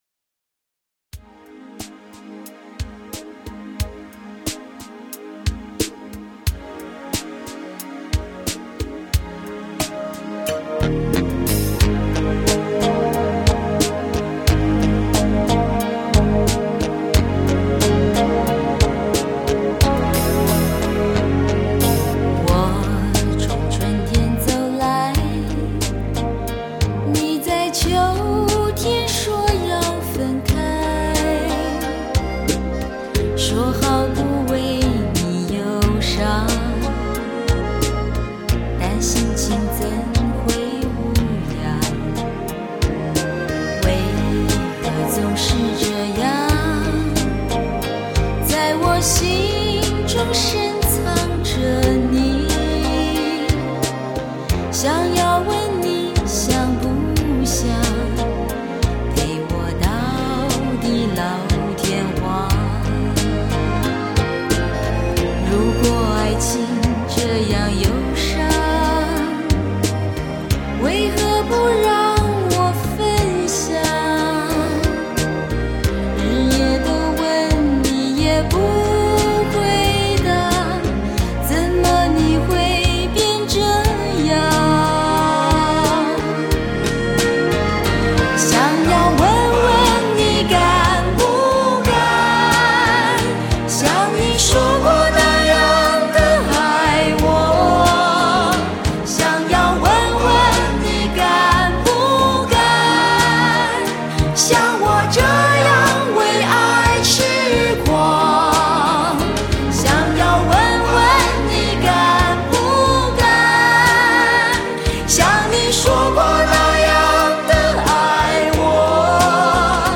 LP黑胶
LP黑胶 精装10碟 HI-FI音质